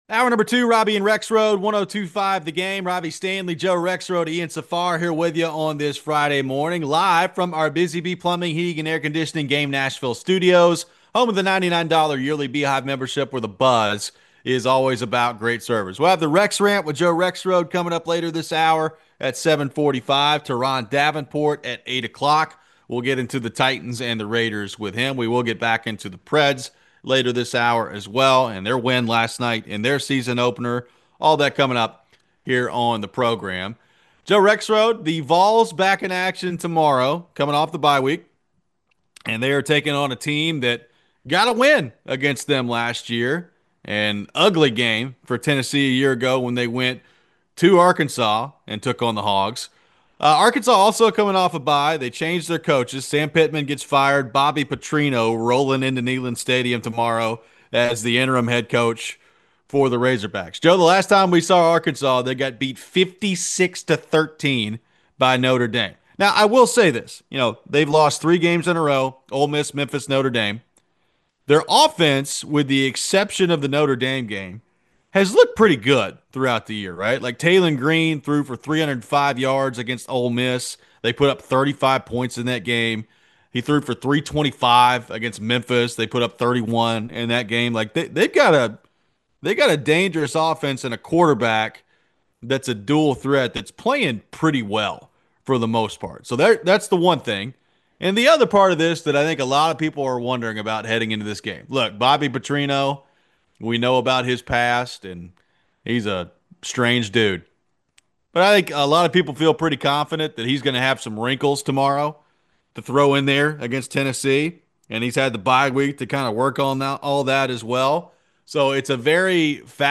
We take your phones.